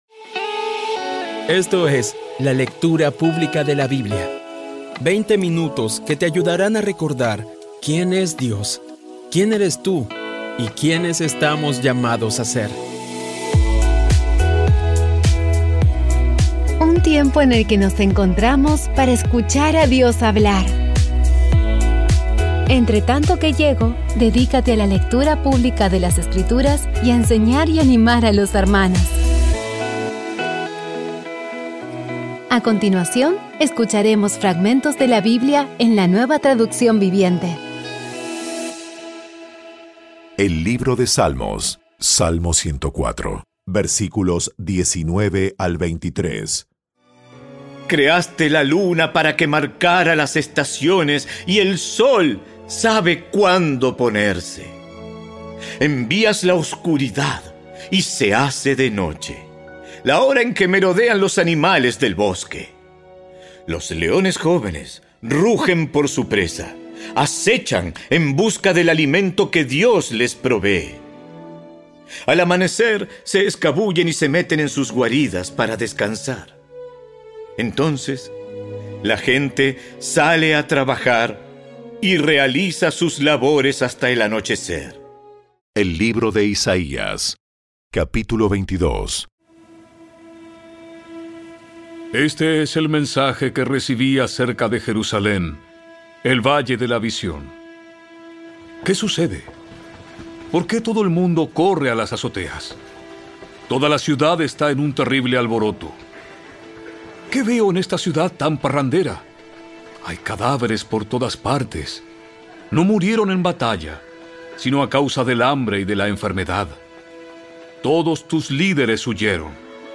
Audio Biblia Dramatizada Episodio 254
Poco a poco y con las maravillosas voces actuadas de los protagonistas vas degustando las palabras de esa guía que Dios nos dio.